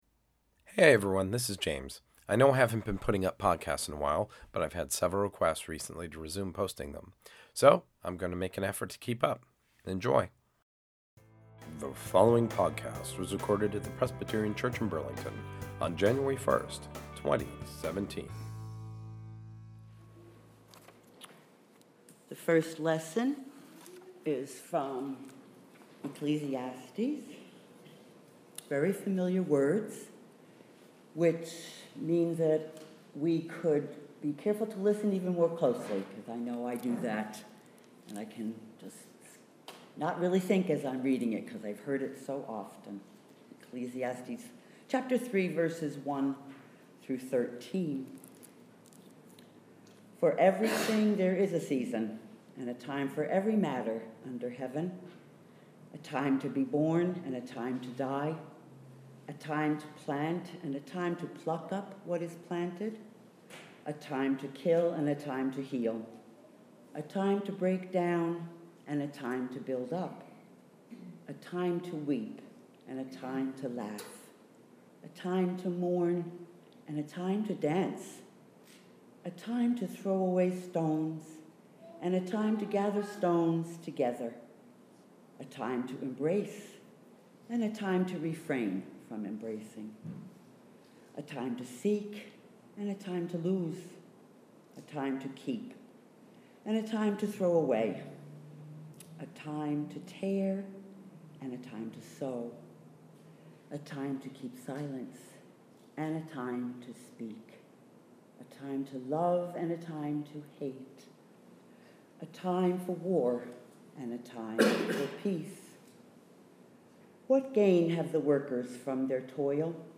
Here’s the meditation from the New Years’ Day service…